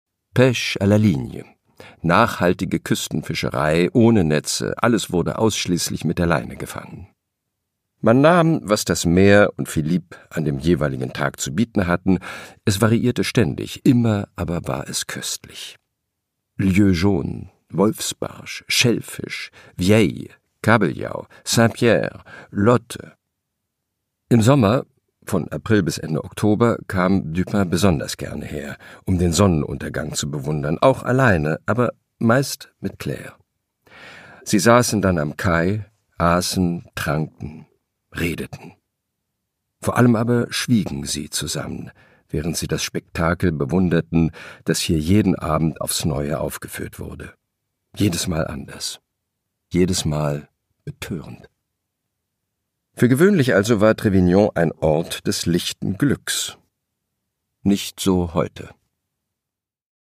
Produkttyp: Hörbuch-Download
Gelesen von: Christian Berkel